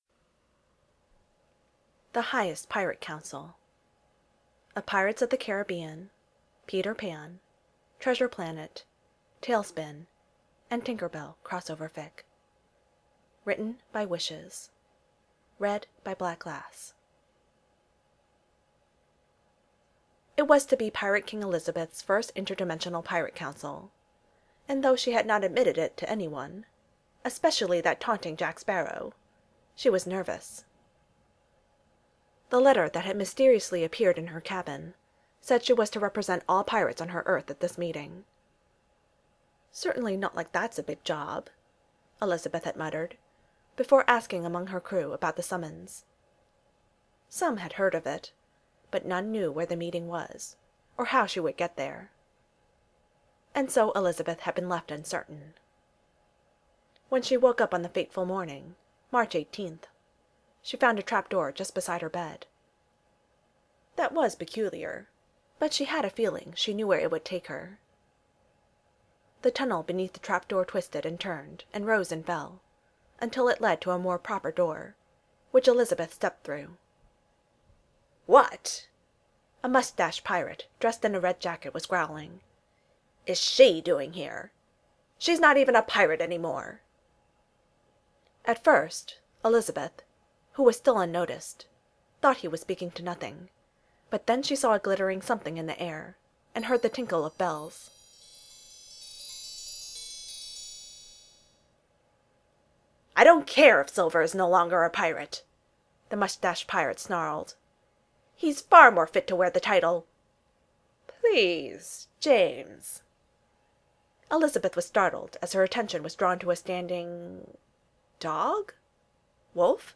mp3 w/o music.
the highest pirate council (no music).mp3